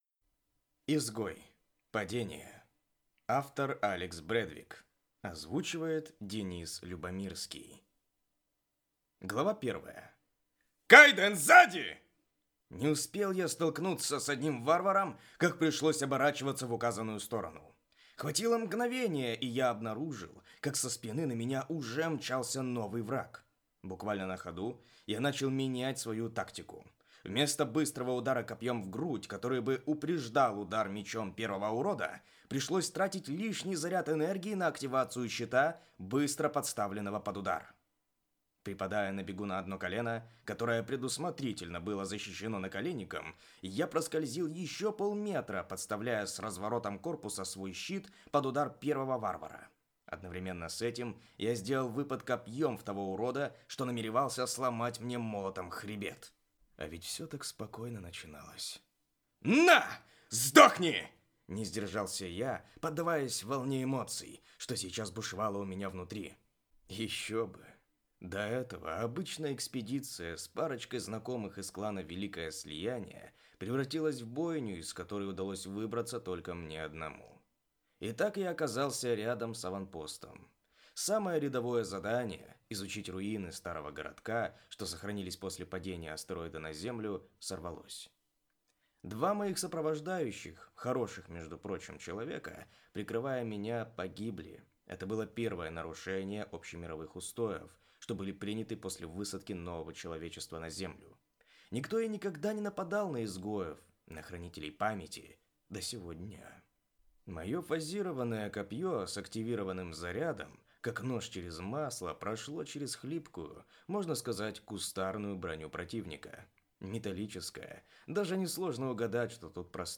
Аудиокнига Изгой. Падение | Библиотека аудиокниг